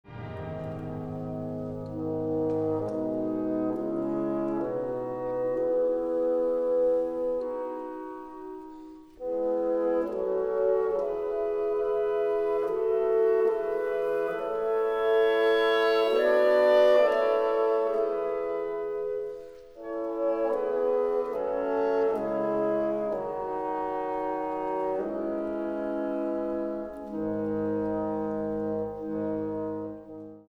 Trascrizione per orchestra di Maurice Ravel